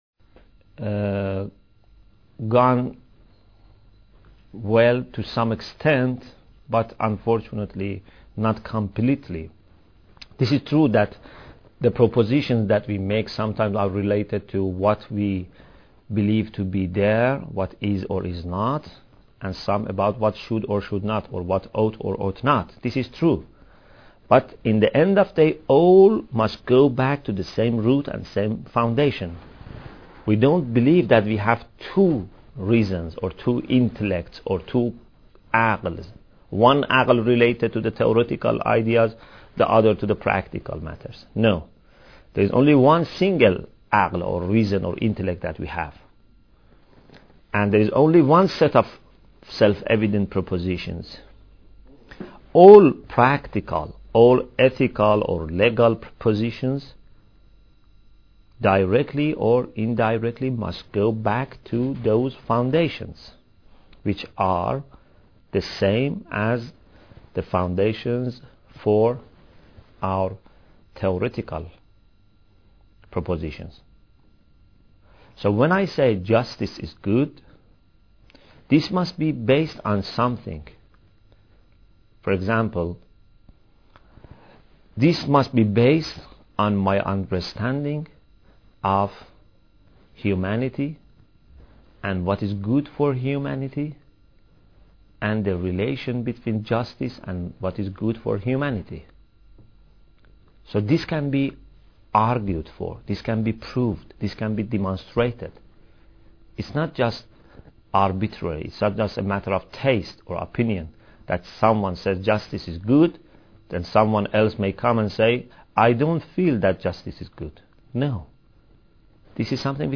Bidayat Al Hikmah Lecture 24